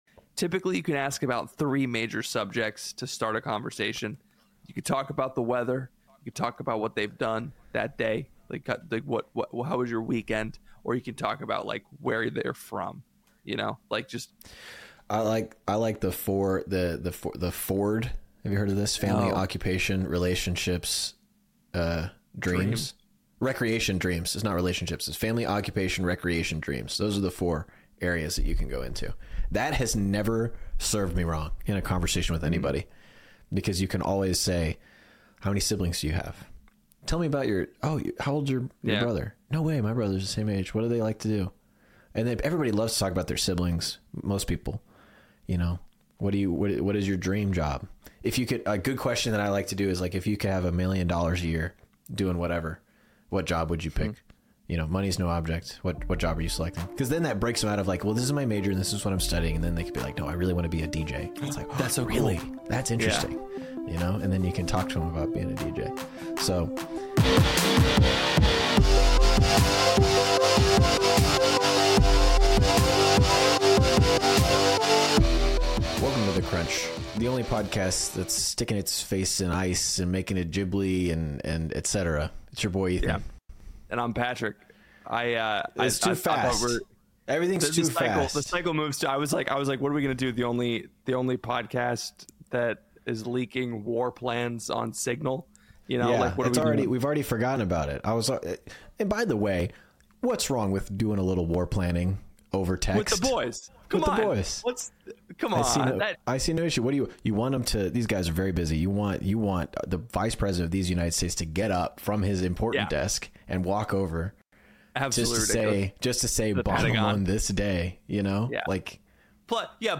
A Comedy Podcast for Young Catholics.